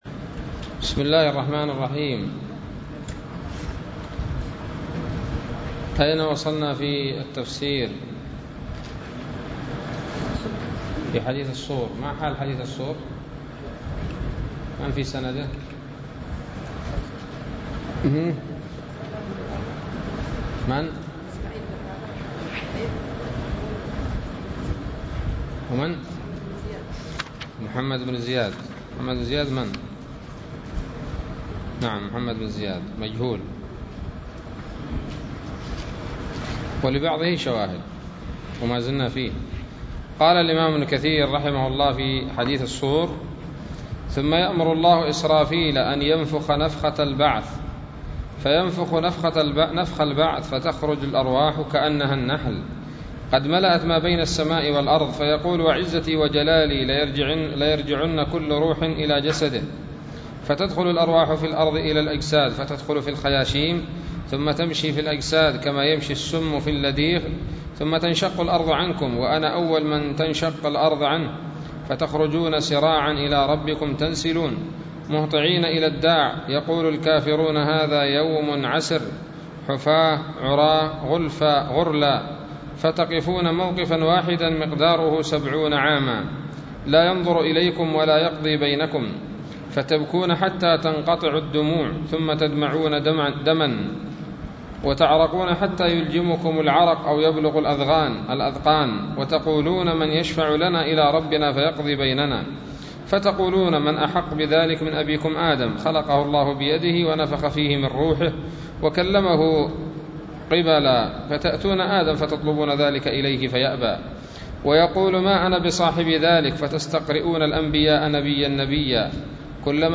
الدرس السادس والعشرون من سورة الأنعام من تفسير ابن كثير رحمه الله تعالى